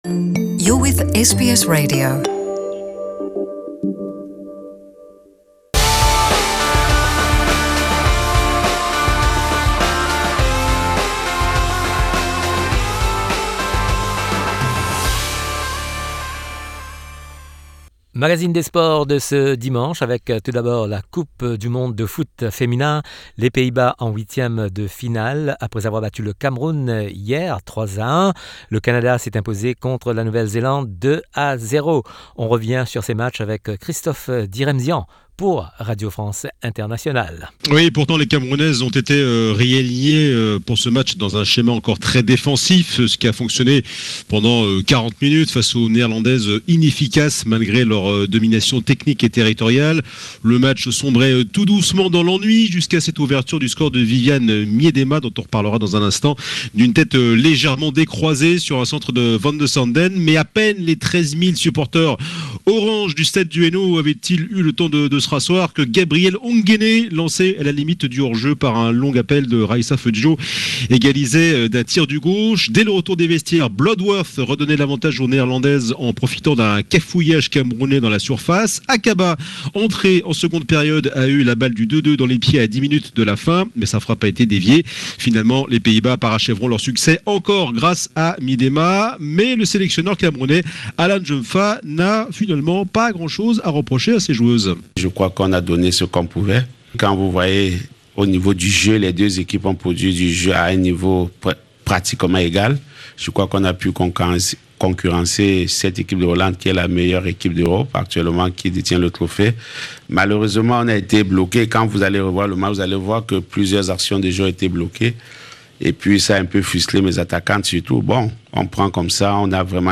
Le journal des sports du dimanche 16 juin